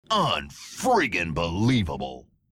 Play, download and share halo reach unfrigginbelievable voice original sound button!!!!
halo-reach-unfrigginbelievable-voice.mp3